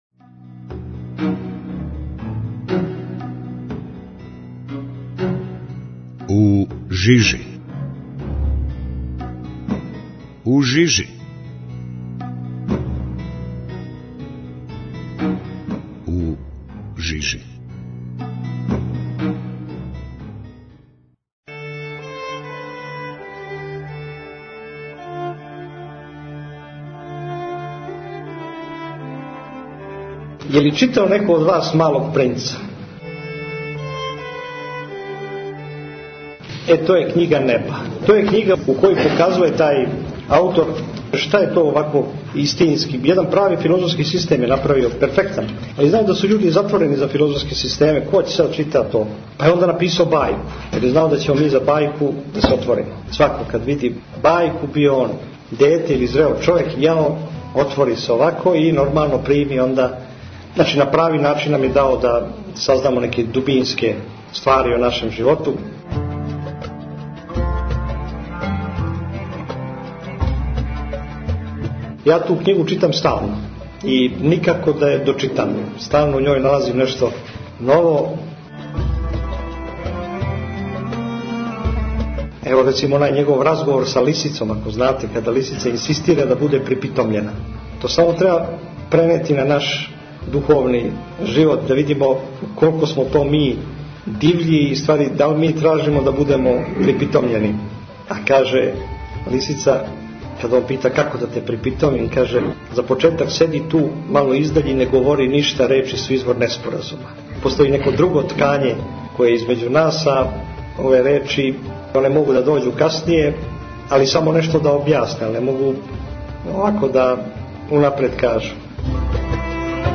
Његово Високопреосвештенство Архиепископ Цетињски Митрополит Црногорско-приморски Г. Амфилохије служио је у недјељу 8. фебруара 2015. године, на Недјељу о Блудном сину, Свету Архијерејску Литургију у манастиру Успенија Пресвете Богогородице на Дајбабској гори.